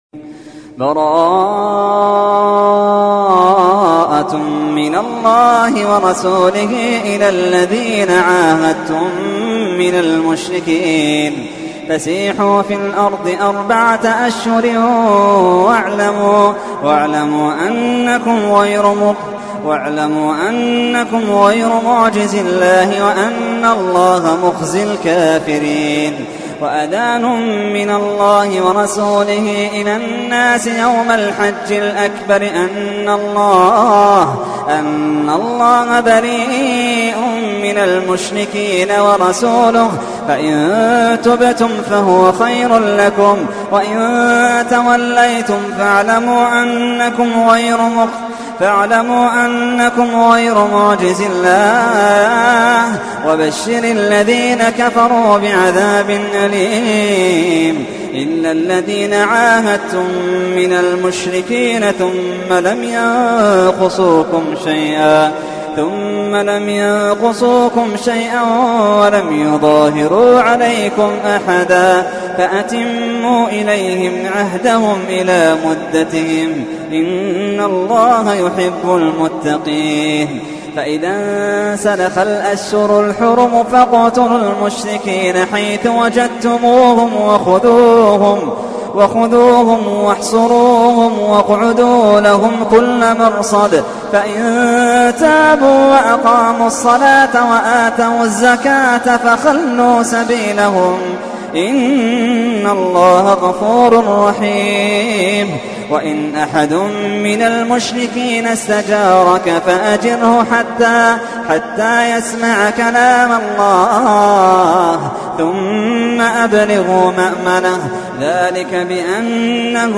تحميل : 9. سورة التوبة / القارئ محمد اللحيدان / القرآن الكريم / موقع يا حسين